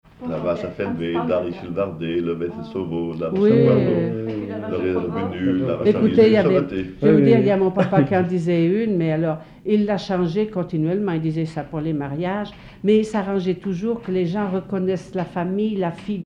Commentaire et comptine en patois
Catégorie Témoignage